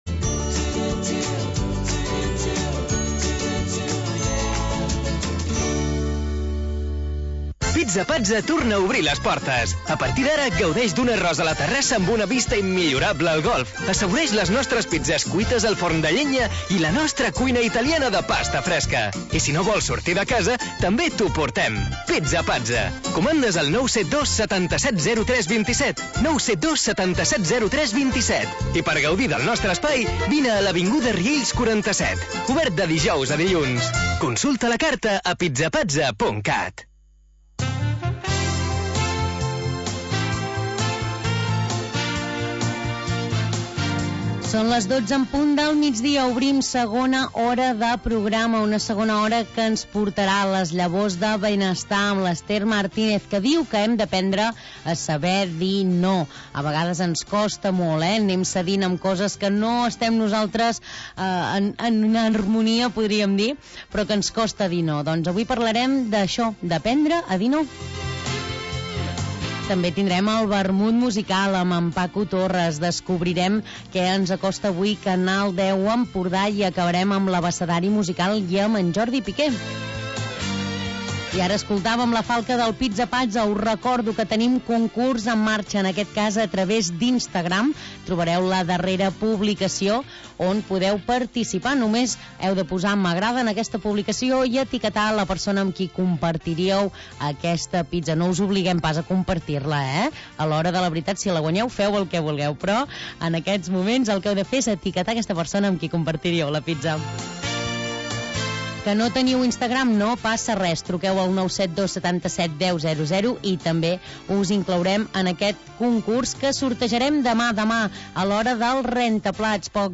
Un magazín d'entreteniment en temps de confinament.